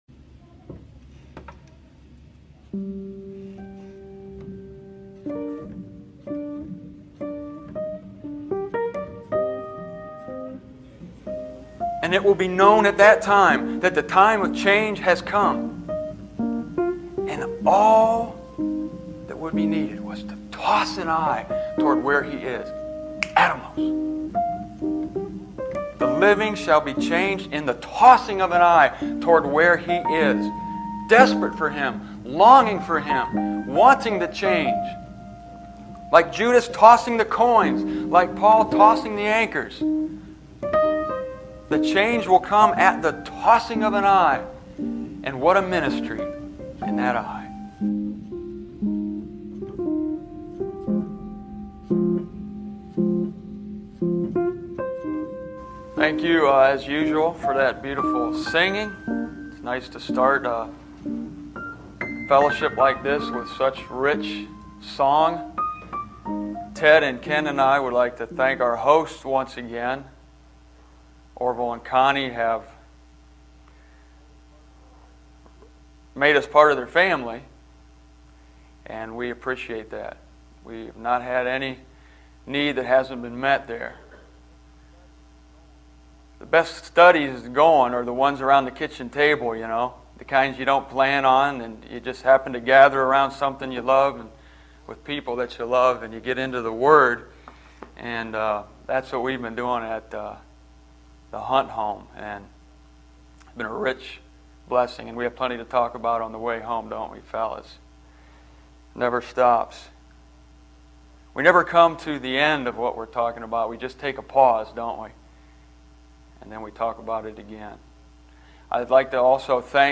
In this video—digitized from a cassette tape audio recording of an address I gave in Grand Rapids, Michigan, back in 1996—I analyze every single occurrence of "rhipto" in its Scriptural context to show you that not only is "twinkle" ridiculous, it hides a startling truth concerning the snatching away that, really, you need to hear.